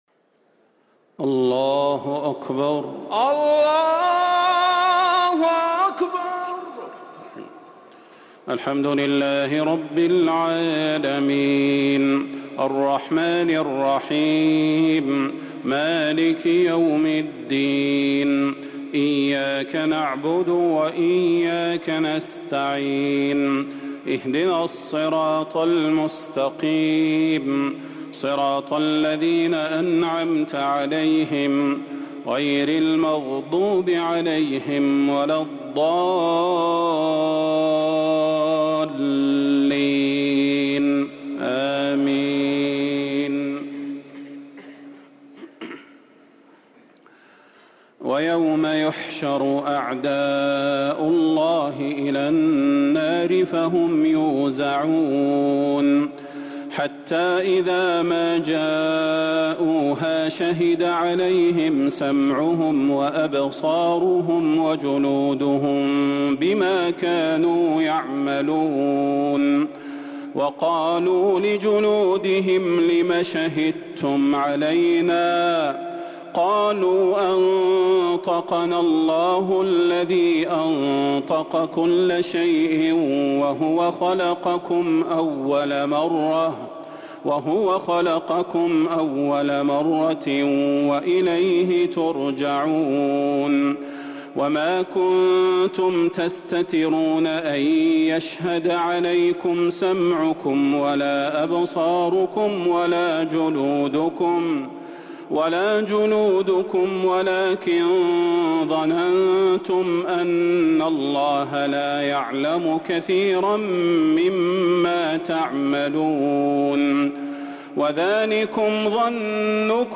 تراويح ليلة 26 رمضان 1444هـ سورة فصلت (19-54) الشورى (1-16) |taraweeh 26st niqht Surah Ghafir_ and Al-Shura 1444H > تراويح الحرم النبوي عام 1444 🕌 > التراويح - تلاوات الحرمين